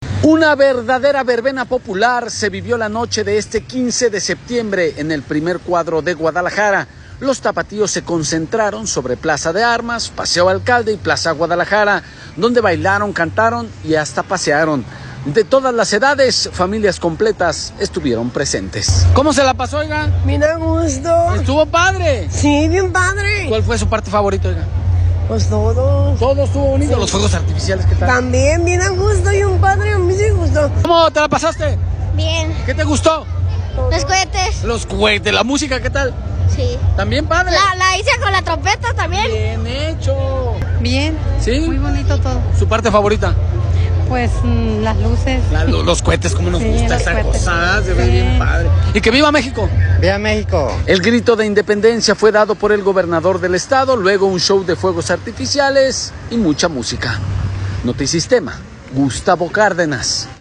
Una verdadera verbena popular se vivió la noche de este 15 de septiembre en el primer cuadro de Guadalajara. Los tapatíos se concentraron sobre plaza de armas, el paseo alcalde y plaza Guadalajara, donde bailaron, cantaron y pasearon.